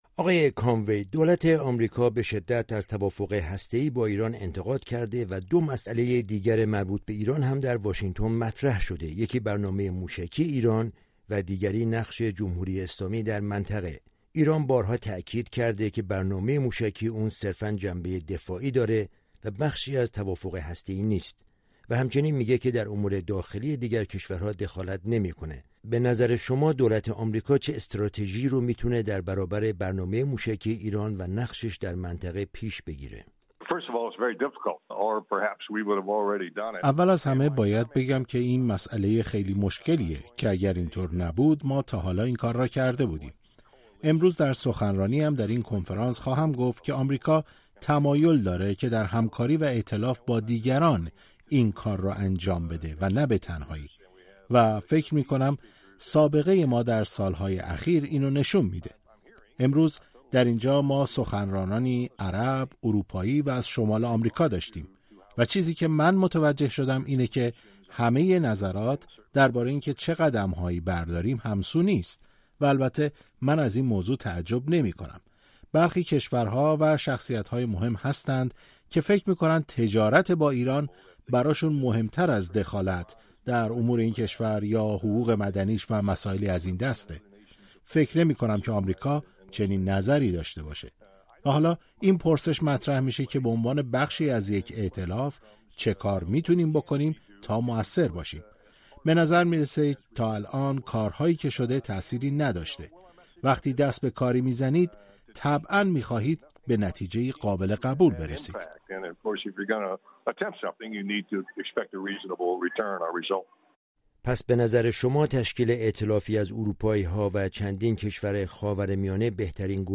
ژنرال جیمز کانوی، فرمانده سابق تفنگداران دریایی آمریکا و یکی از فرماندهان ارشد ارتش این کشور در حمله به عراق در سال ۲۰۰۳، در گفت‌وگویی اختصاصی با رادیو فردا به پرسش‌هایی درباره سیاست آمریکا در قبال ایران و تجربیاتش در عراق پاسخ داده است.